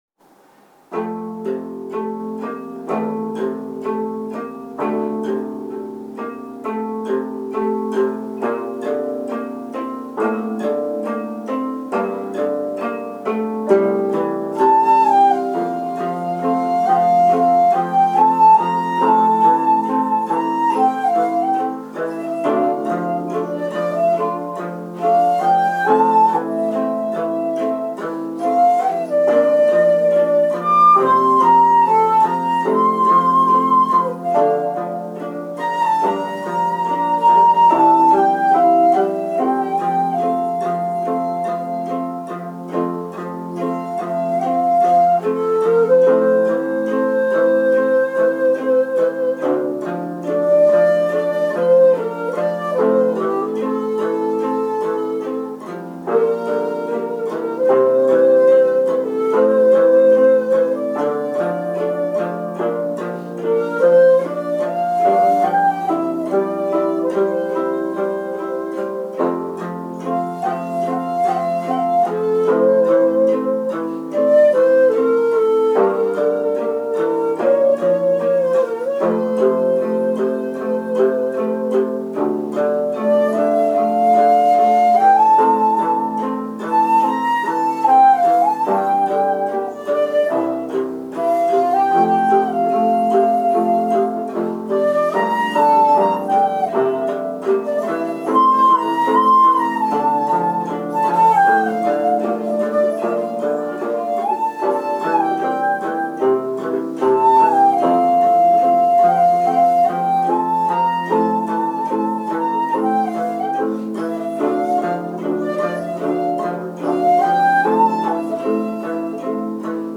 十七弦も純正調できちんと合わせればなかなか美しい響きがでます。